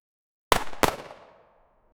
Guns Sound Effects - Free AI Generator & Downloads
zastava arms zpap92 fire one shot up close sound